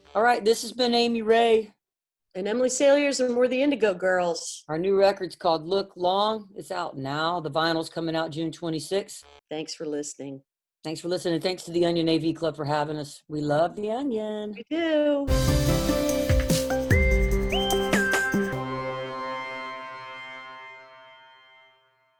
lifeblood: bootlegs: 2020: 2020-06-24: house shows series - the av club - the onion
07. talking with the crowd (0:22)